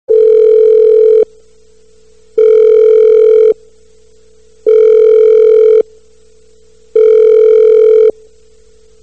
Download Phone Ring sound effect for free.
Phone Ring